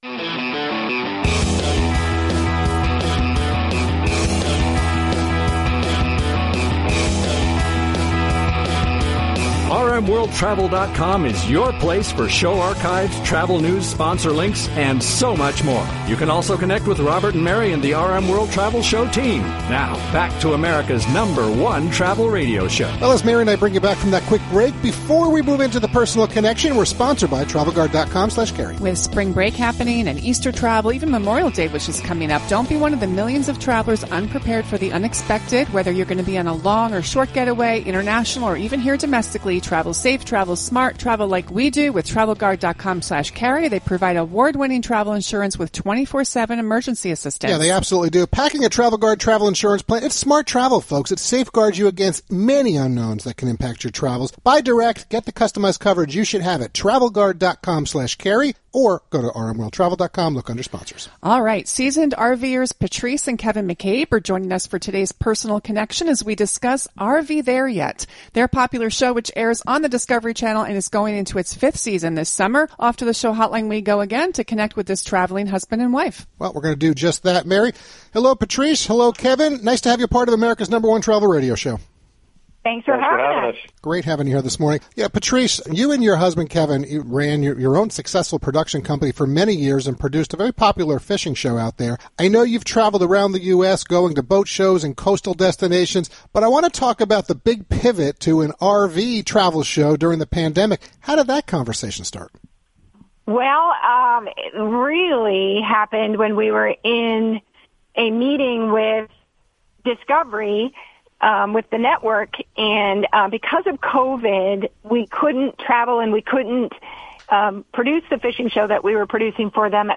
part of the live national broadcast of America’s #1 Travel Radio Show
transmitted over satellite and aired across the USA via our 615+ AM & FM radio stations